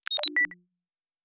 pgs/Assets/Audio/Sci-Fi Sounds/Interface/Data 02.wav at master